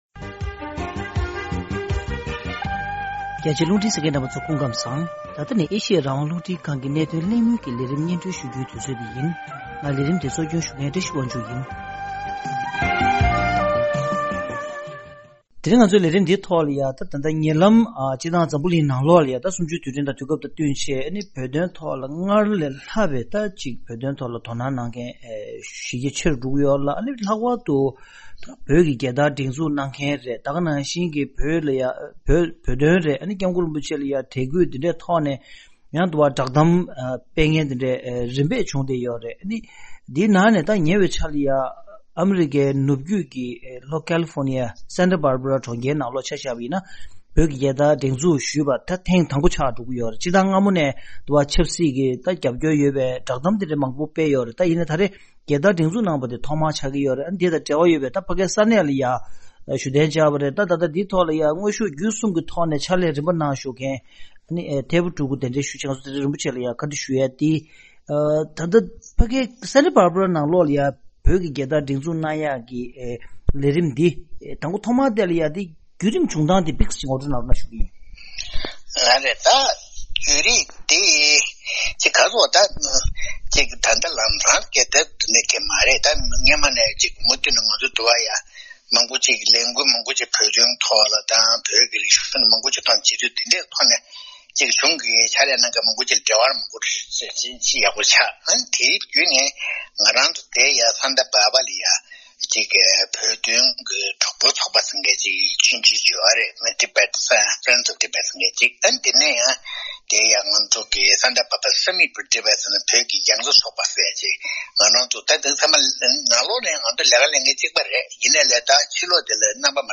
གནད་དོན་གླེང་མོལ་གྱི་ལས་རིམ